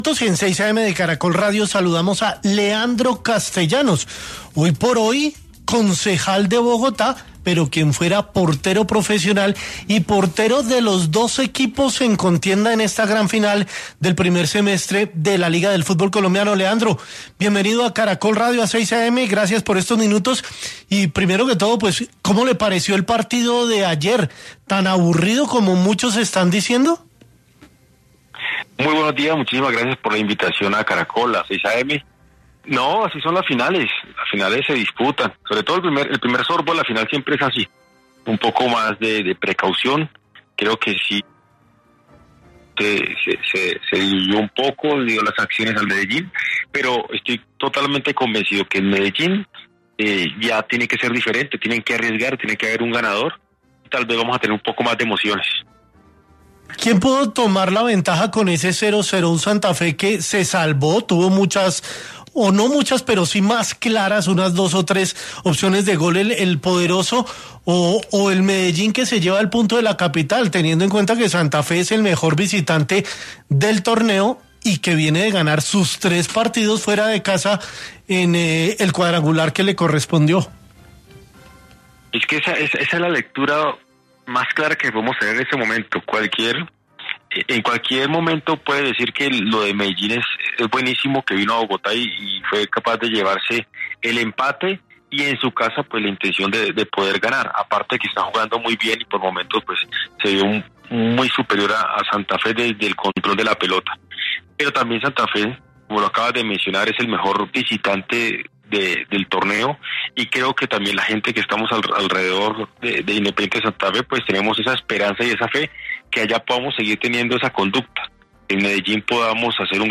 Castellanos, en diálogo con 6AM Hoy Por Hoy de Caracol Radio, se refirió a lo que fue el juego de ida entre ambos equipos en Bogotá, partido que terminó empatado 0-0.